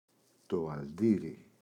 αλντίρι, το [alꞋdiri]